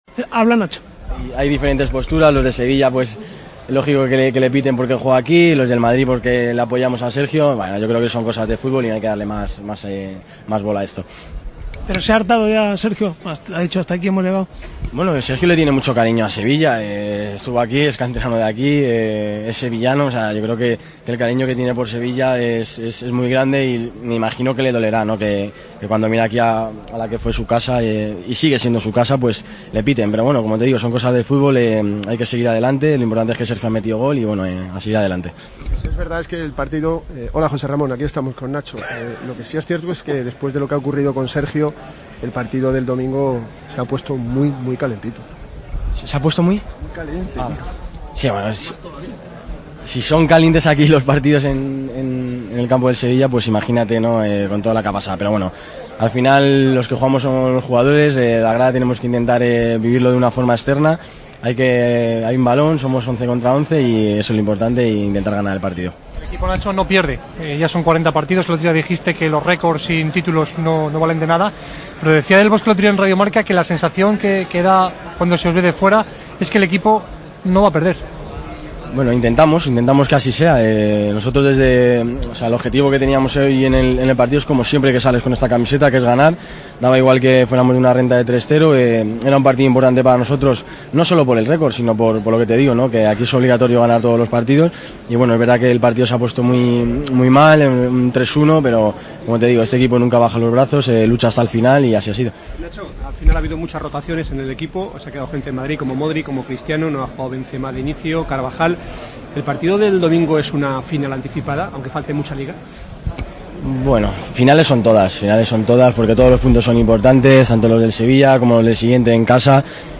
AUDIO: Escuchamos al defensa del Real Madrid tras pasar a cuartos de final después de empatar con en Sevilla 3-3